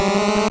Cri de Queulorior dans Pokémon Or et Argent.